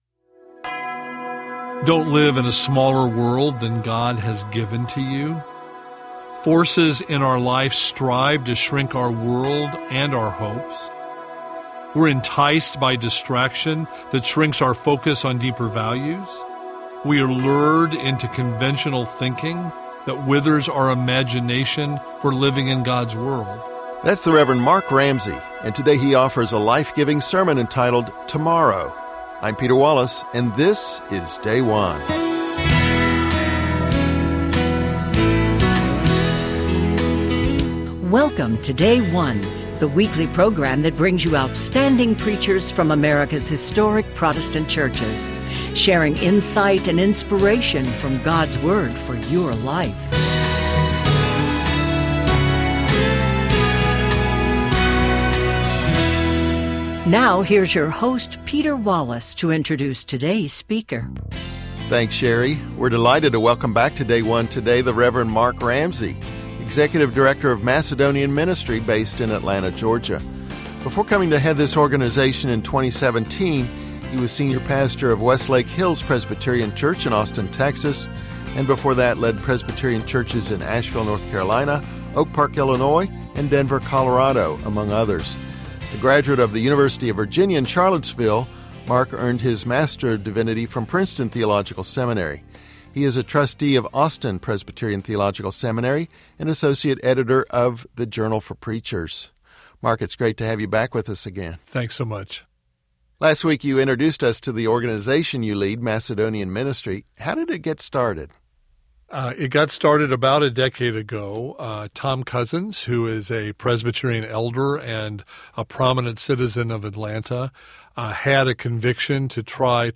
10th Sunday after Pentecost - Year C Hebrews 11:32--12:2